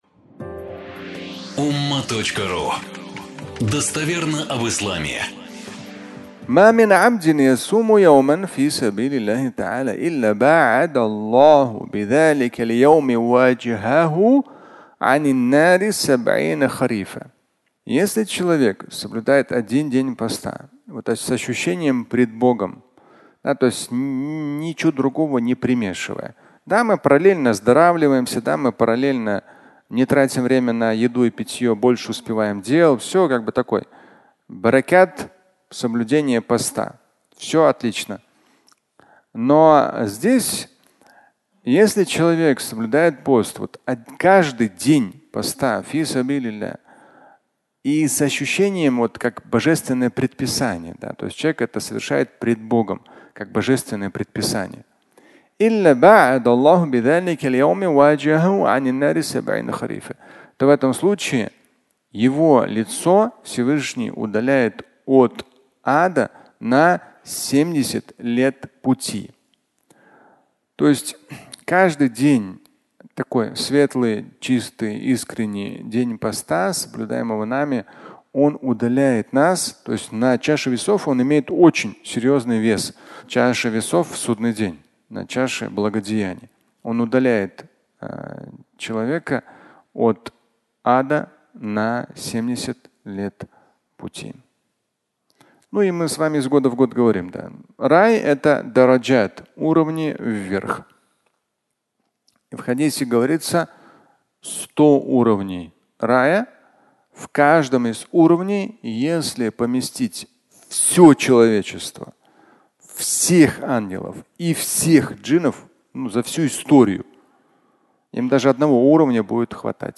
70 лет пути (аудиолекция)